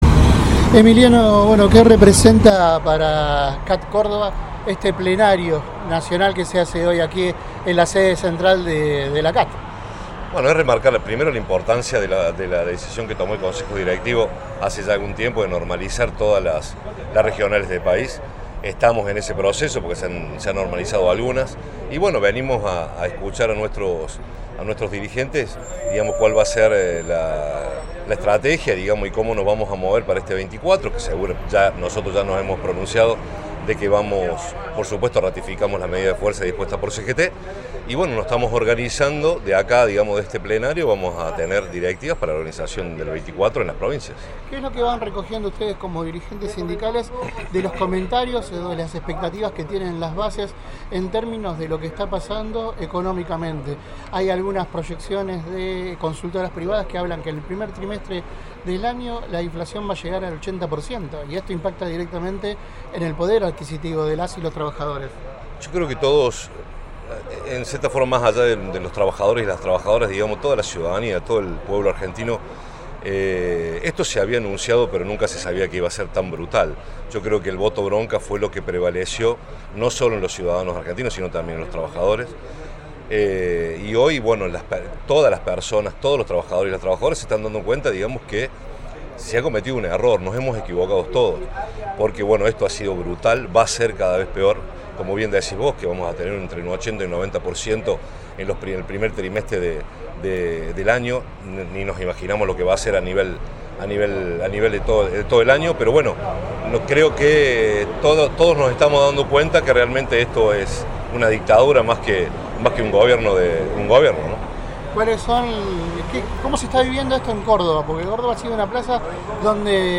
A continuación compartimos el diálogo completo